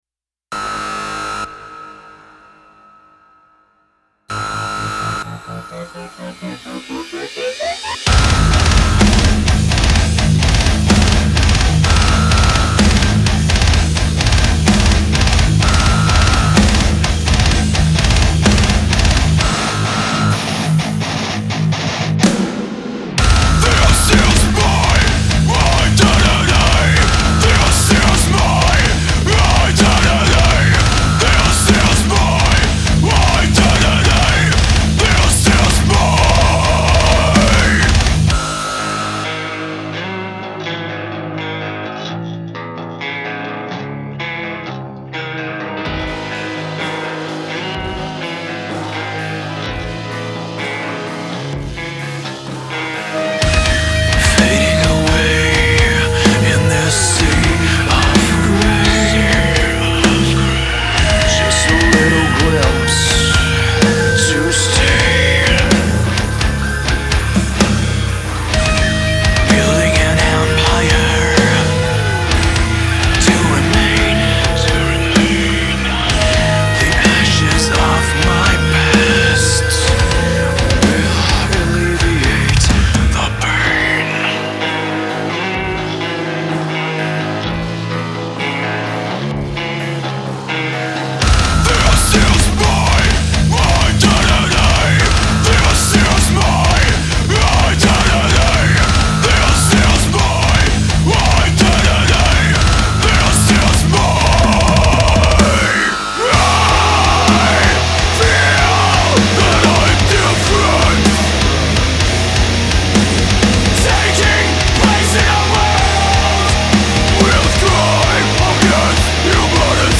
Industrial